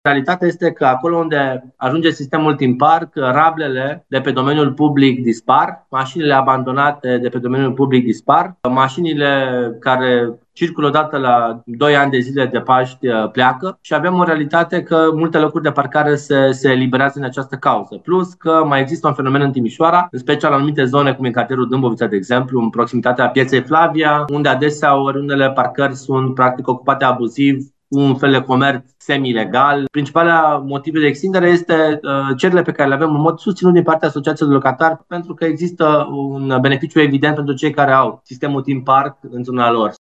Viceprimarul Ruben Lațcău spune că decizia de extindere a sistemului de parcare cu plată a fost luată ca urmare a cererilor venite din partea asociațiilor de locatari din zonă.